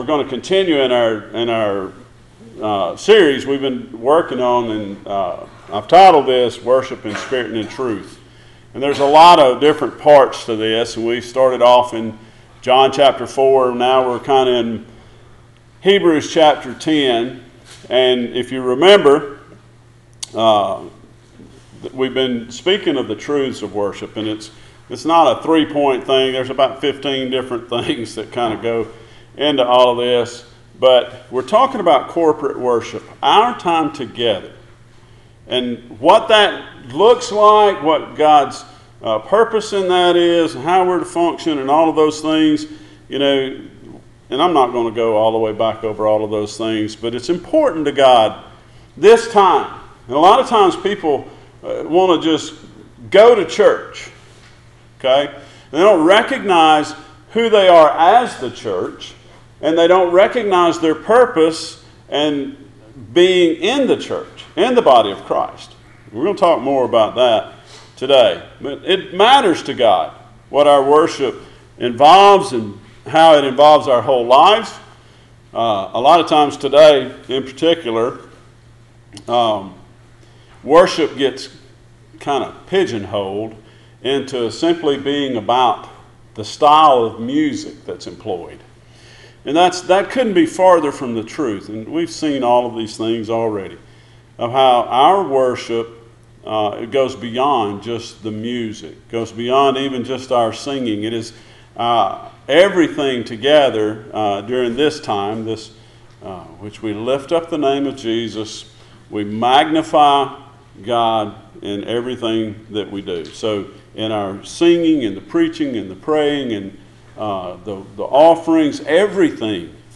Passage: Hebrews 10:19-25 Service Type: Sunday Morning